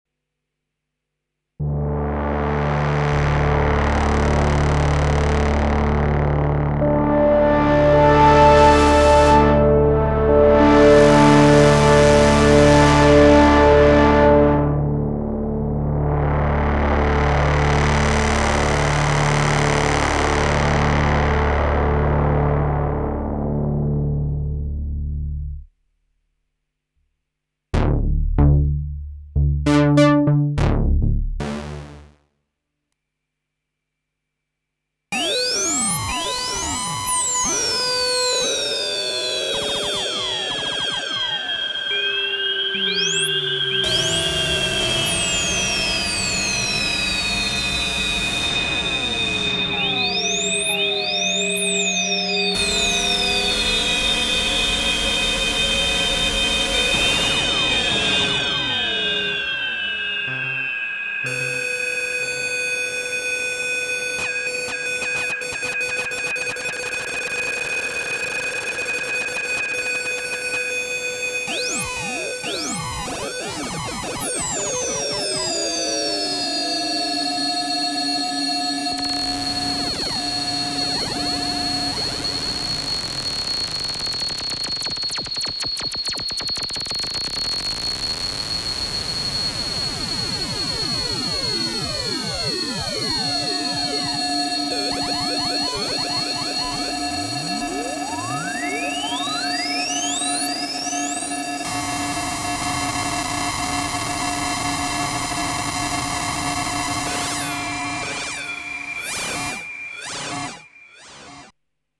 1: PWM MOD
Dies sind MODoscs oder LFOs, keine VCOS, die sind frei!
Aber - man braucht schnelle Modulation - ja, es ginge auch mit FM, ich habe im ersten Beispiel Random als Rauschfeinheit reingebaut, im zweiten puristischer - als Verlauf, um es genauer zu analysieren.